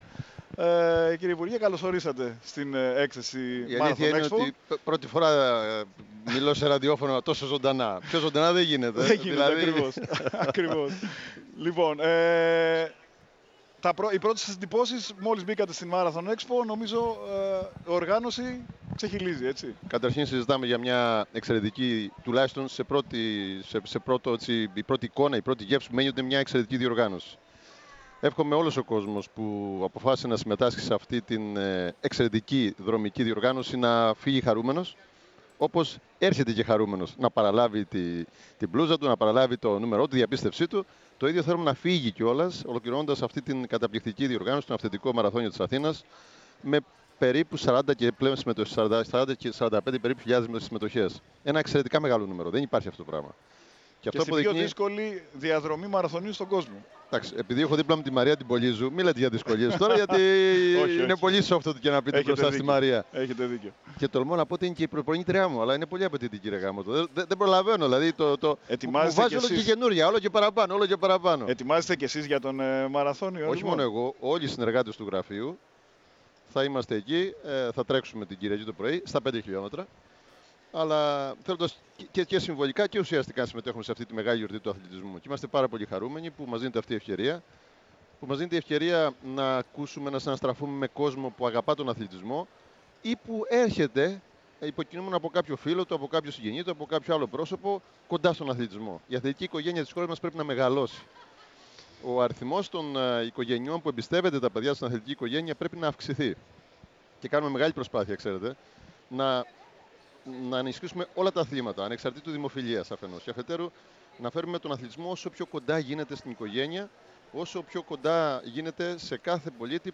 O Υφυπουργός Αθλητισμού, μίλησε στον αέρα της ΕΡΑ ΣΠΟΡ από τα εγκαίνια του Marathon Expo, για τον Αυθεντικό Μαραθώνιο, αλλά και τη δική του συμμετοχή σ’ αυτόν.